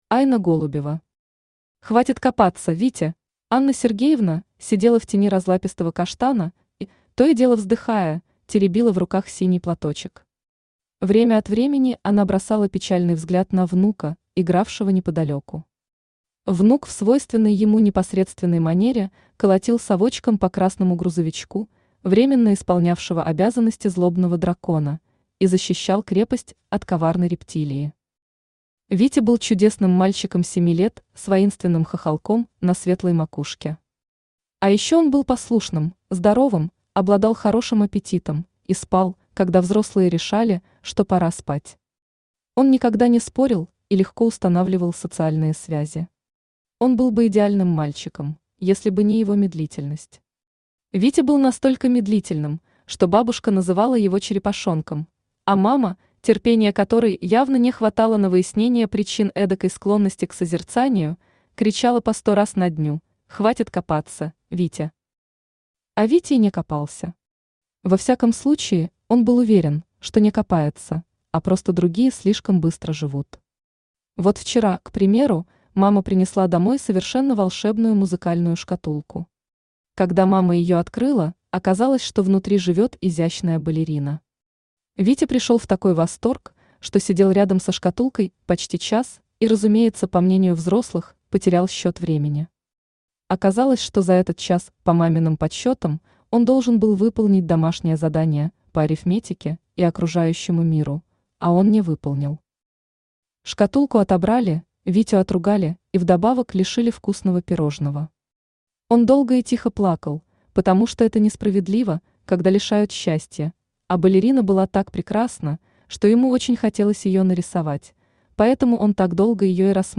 Аудиокнига Хватит копаться, Витя!
Автор Айна Голубева Читает аудиокнигу Авточтец ЛитРес.